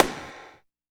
16 AMB SNR.wav